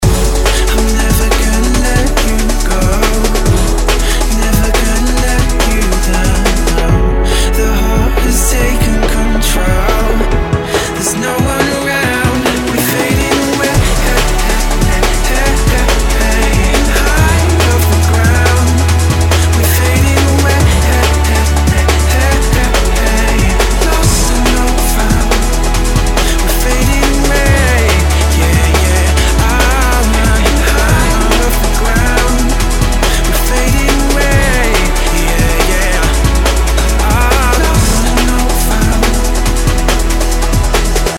• Качество: 192, Stereo
Очень красивая музыка и вокал в сочетании